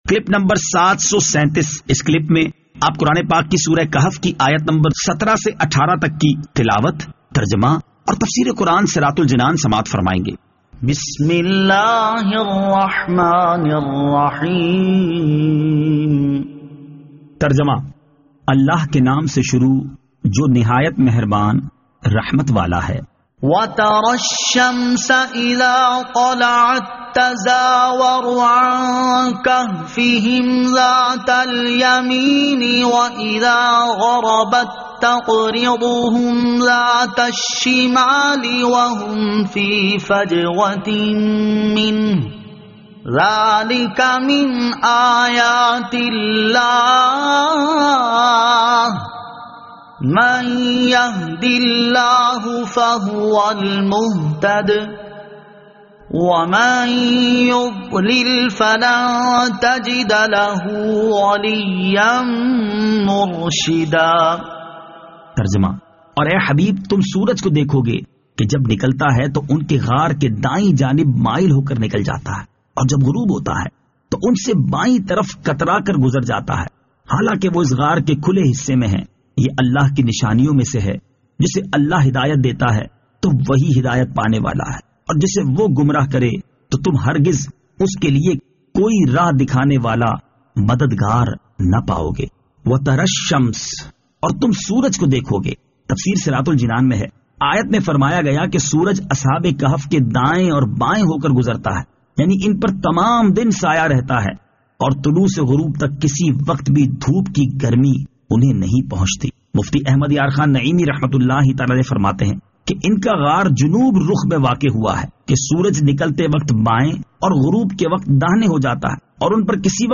Surah Al-Kahf Ayat 17 To 18 Tilawat , Tarjama , Tafseer
2021 MP3 MP4 MP4 Share سُوَّرۃُ الکَھْفِ آیت 17 تا 18 تلاوت ، ترجمہ ، تفسیر ۔